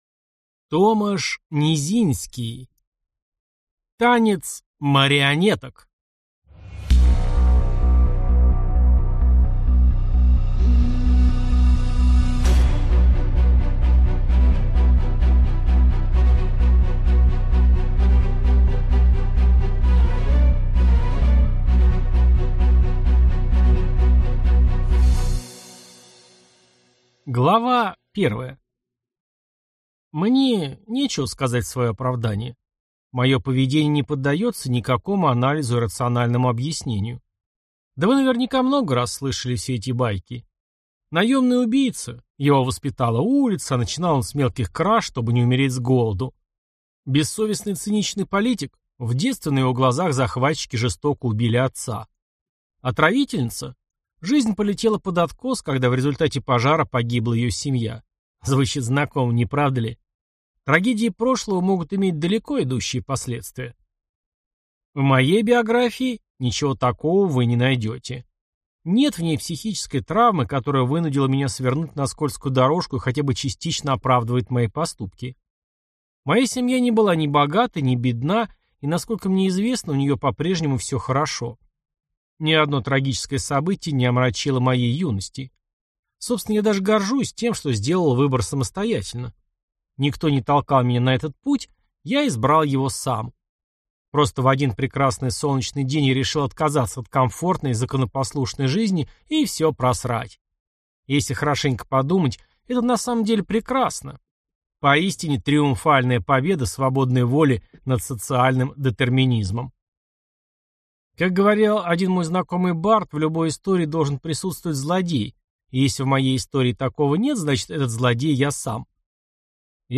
Аудиокнига Танец марионеток | Библиотека аудиокниг
Прослушать и бесплатно скачать фрагмент аудиокниги